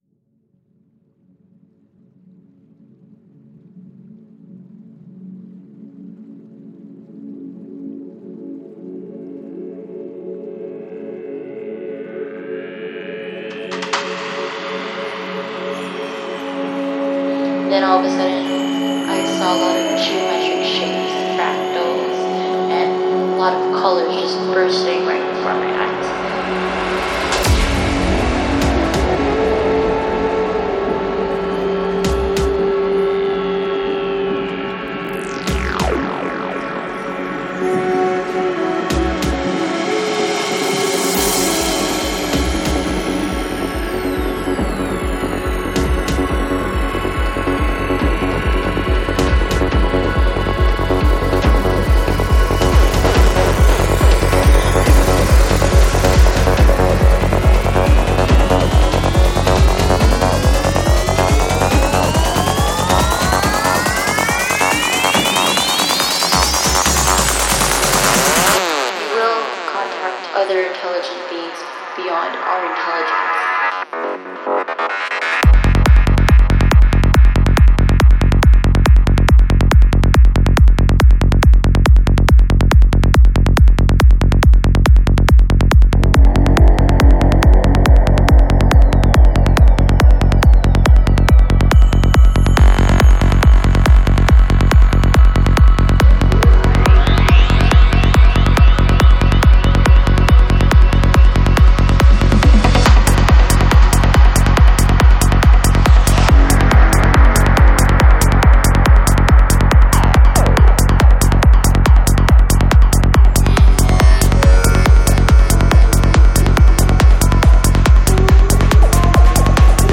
Жанр: Psychedelic
Psy-Trance